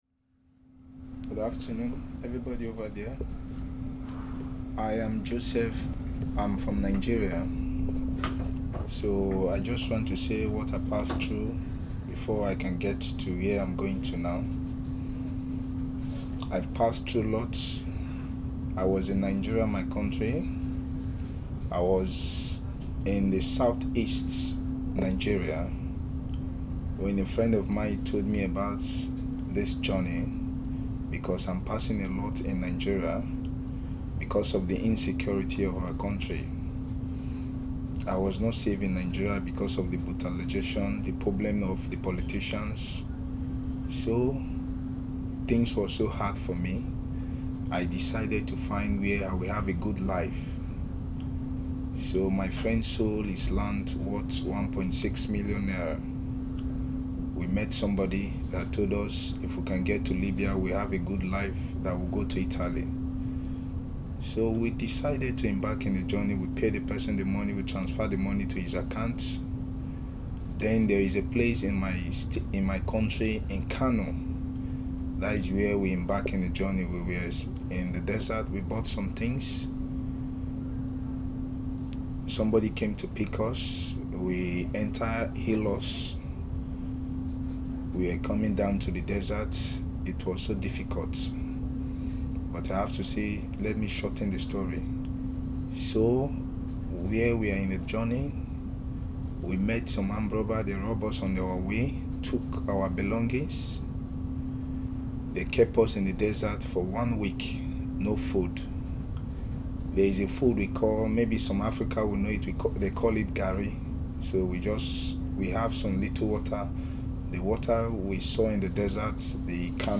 The audio has been lightly edited to remove identifying details.
Part of the Migration Sounds project, the world’s first collection of the sounds of human migration.